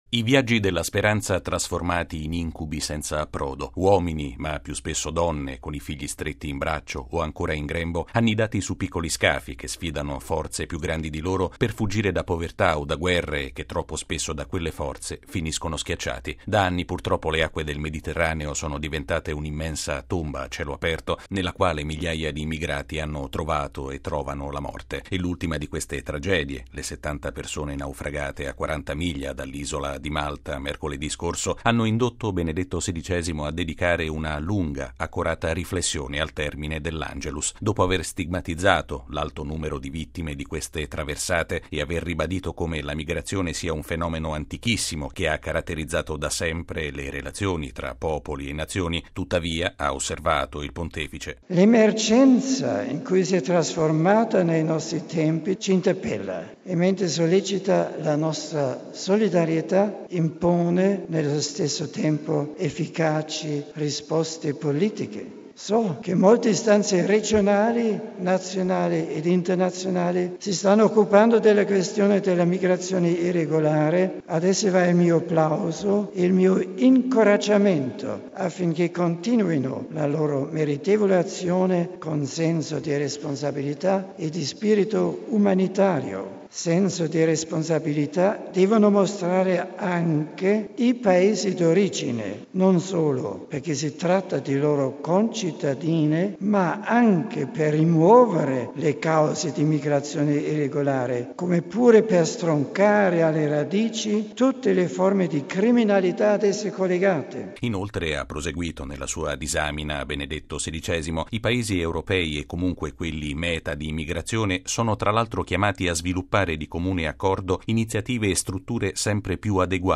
Di fronte alla consueta folla radunata del cortile, che lo ha più volte acclamato e applaudito, il Papa - colpito dalle ultime, drammatiche pagine di cronaca riguardanti gli immigrati - ha chiesto con forza ai Paesi di approdo di aprire le proprie porte con spirito umanitario e a quelli Paesi di partenza di “stroncare alle radici” quanto di criminale c’è dietro tali viaggi, che mettono a repentaglio la vita di migliaia di persone.